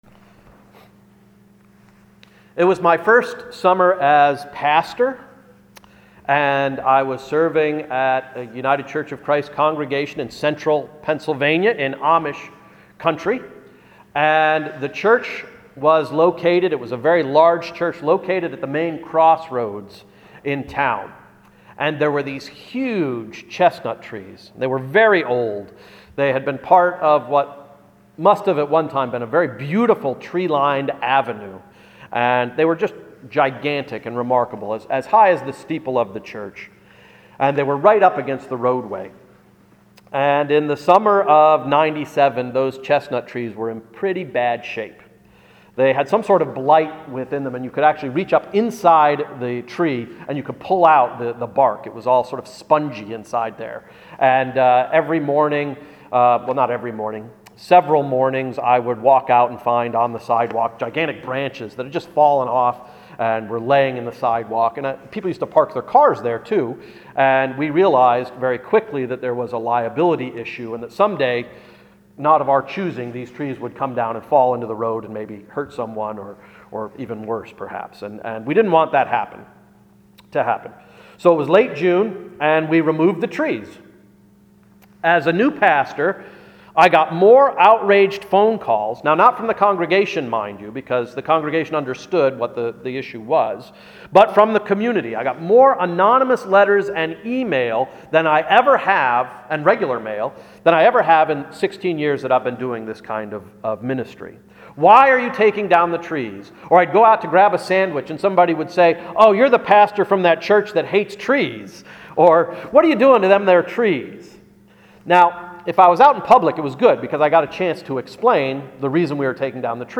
Sermon of January 20th, 2013–“Spiritual Struggles” – Emmanuel Reformed Church of the United Church of Christ